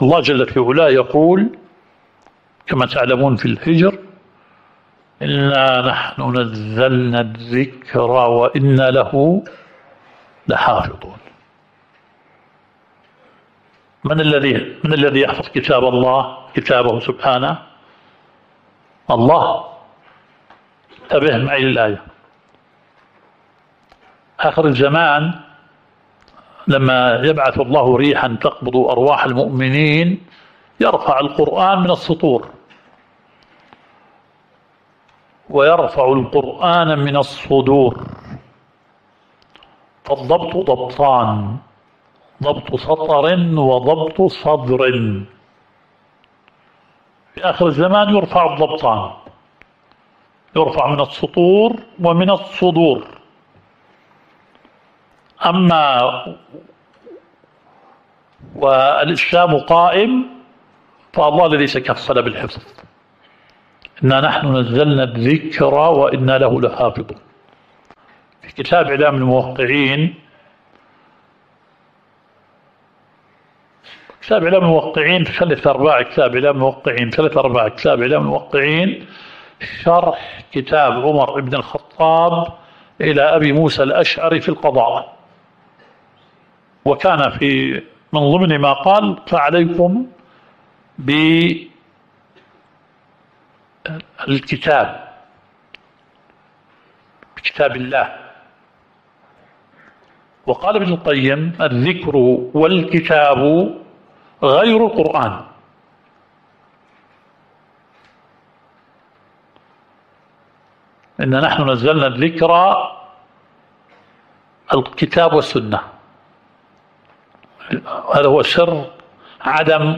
الدورة الشرعية الثالثة للدعاة في اندونيسيا – منهج السلف في التعامل مع الفتن – المحاضرة الرابعة.